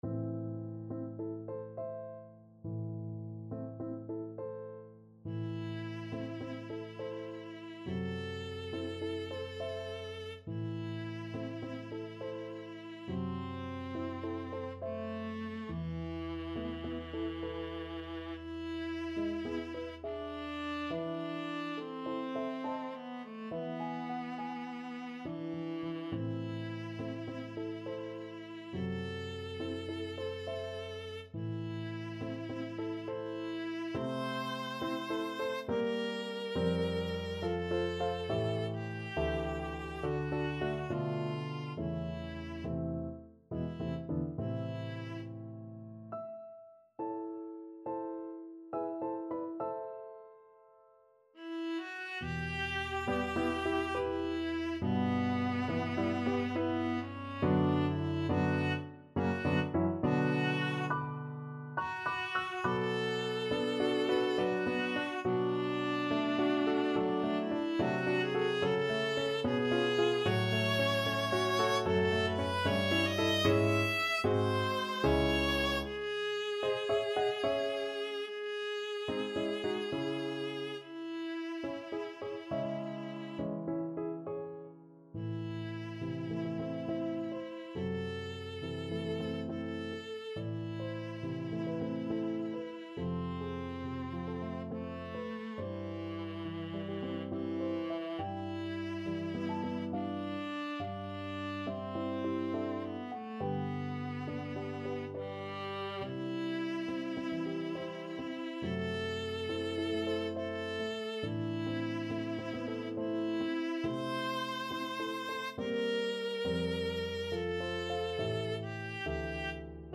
3/8 (View more 3/8 Music)
Andante =69
Classical (View more Classical Viola Music)